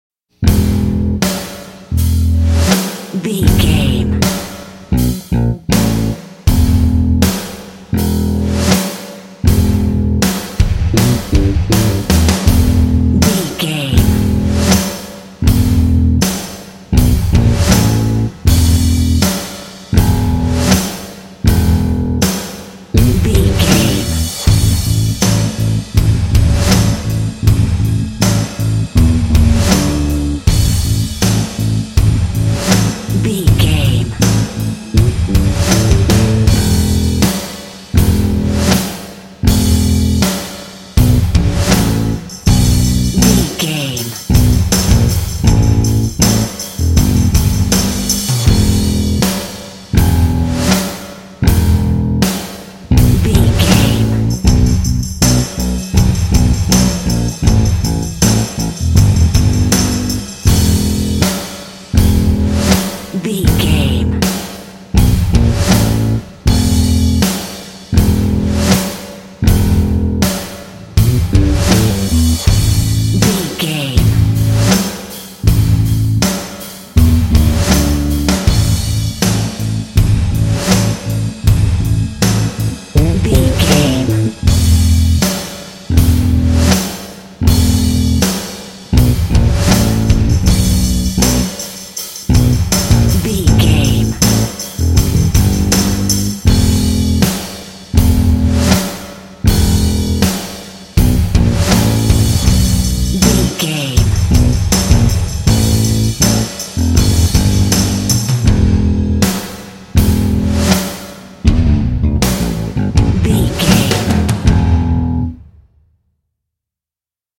Aeolian/Minor
bass guitar
drum machine
electric guitar
percussion
aggressive
intense
groovy
energetic
heavy